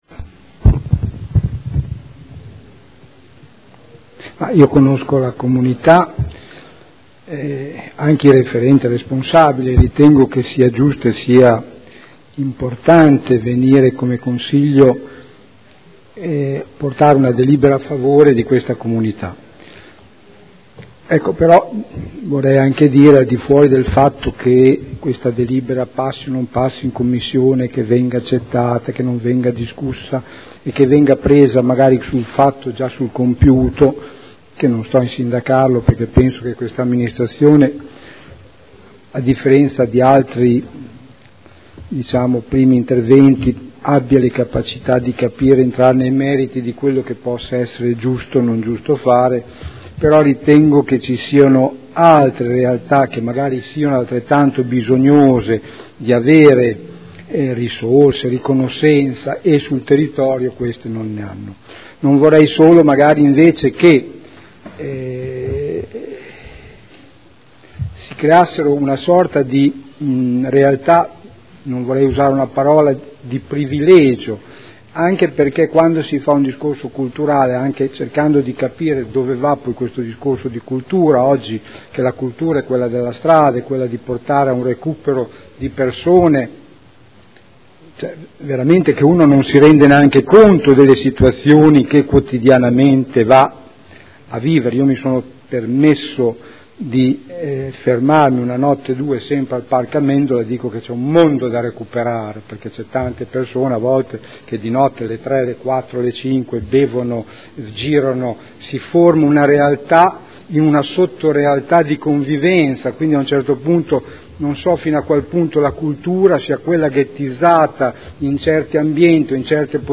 Dibattito su proposta di deliberazione.